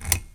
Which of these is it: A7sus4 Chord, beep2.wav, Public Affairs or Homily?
beep2.wav